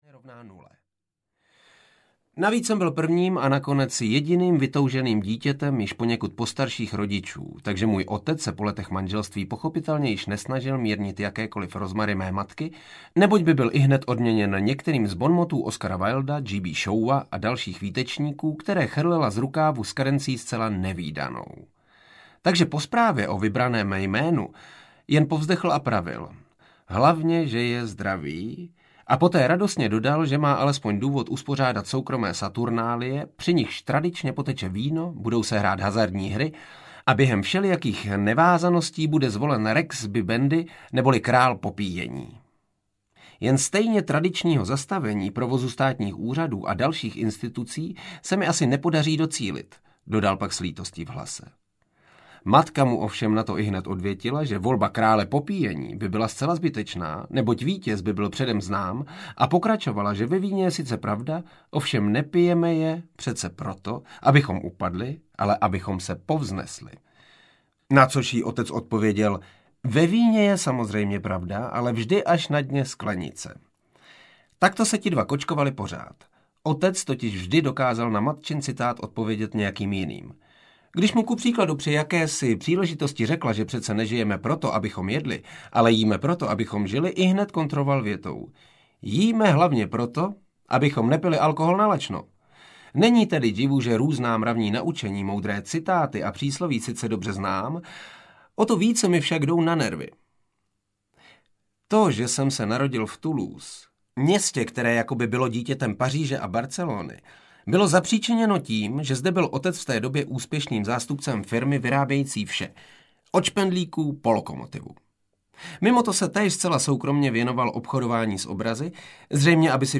Saturnin se představuje audiokniha
Ukázka z knihy
• InterpretJaromír Nosek